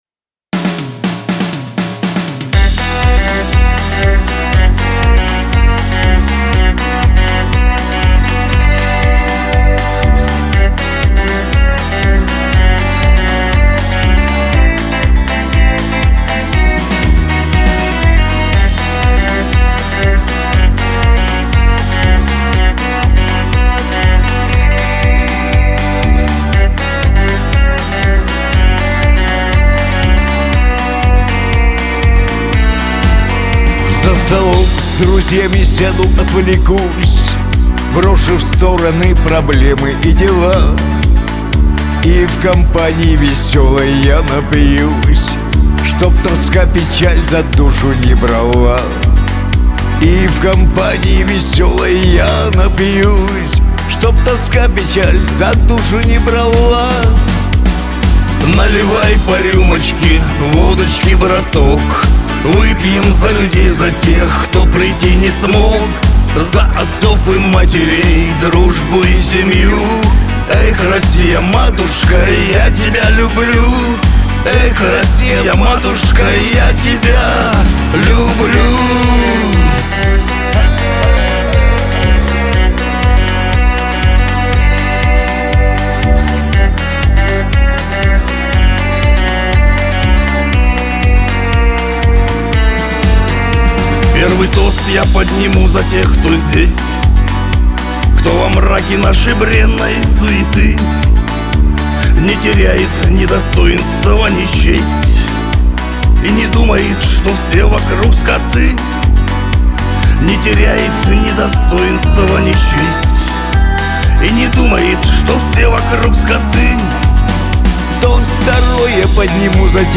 Шансон
дуэт